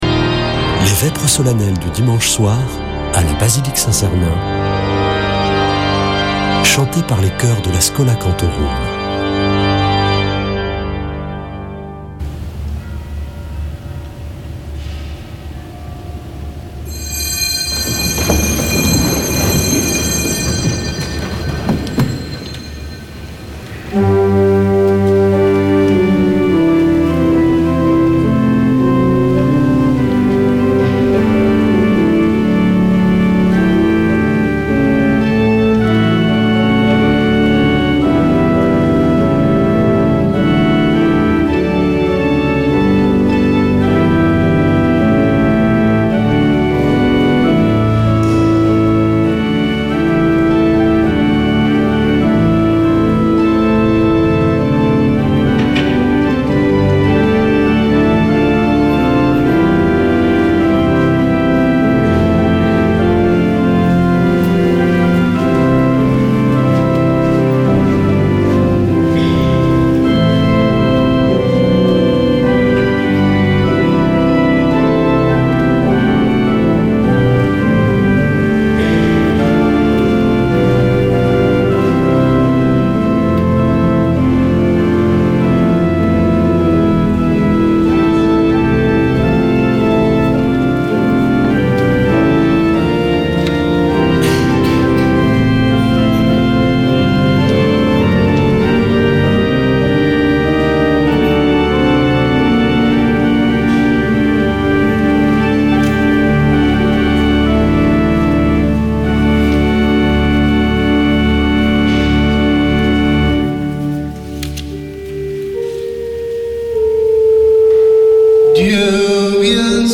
Vêpres de Saint Sernin du 01 oct.